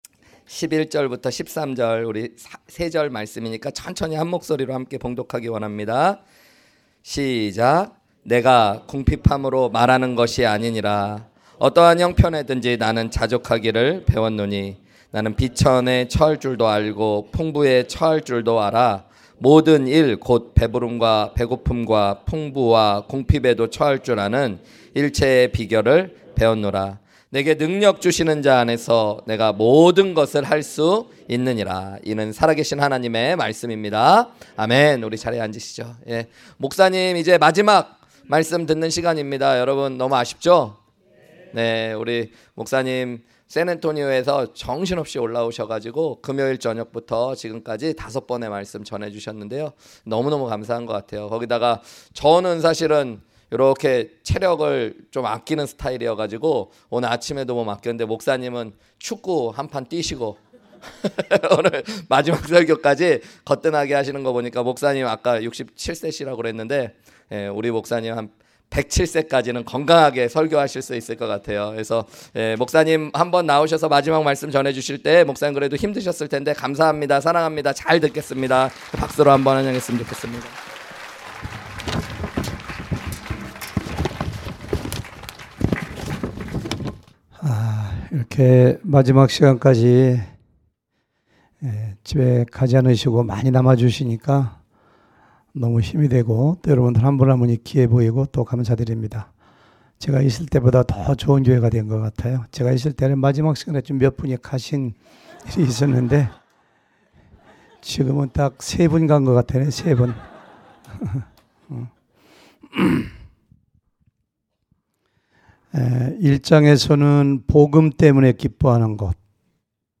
주일 외 설교
2022 H-Camp 둘째날 주일 오후 설교